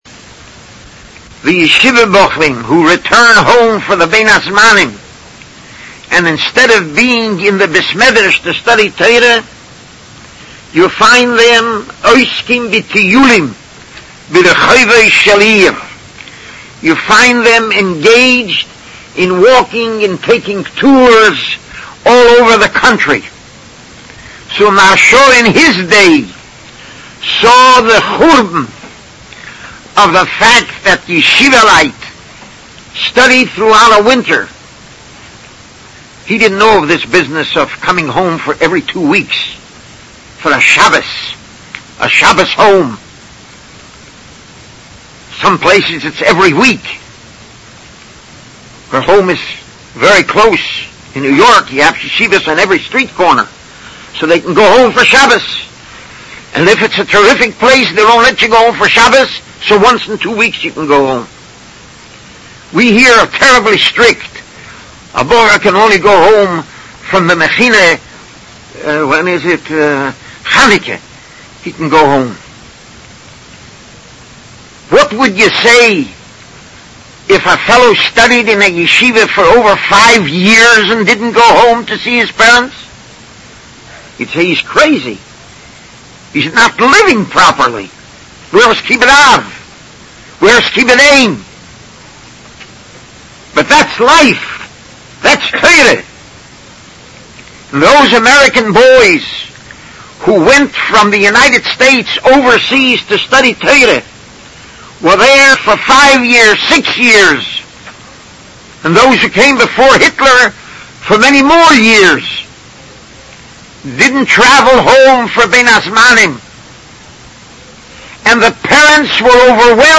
Recent Sermons